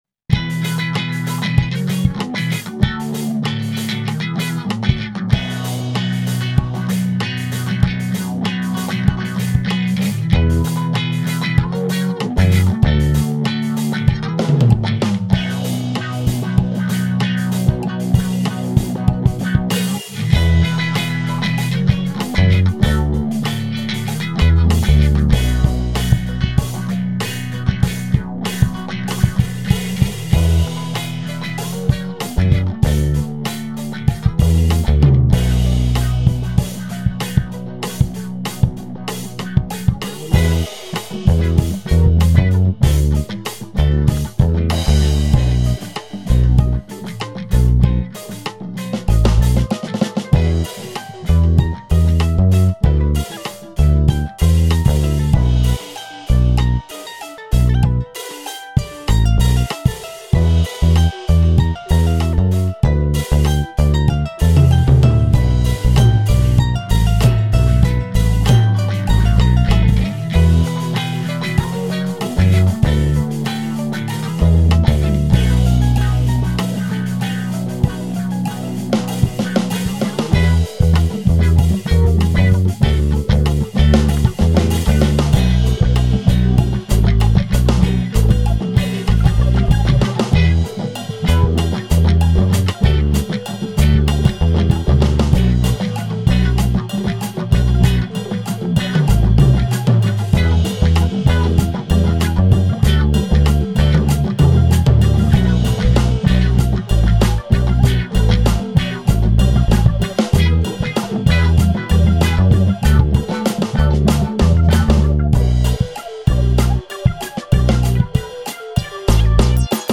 Free Brazilian Home Made Indie Music
World music
Indy
Jazz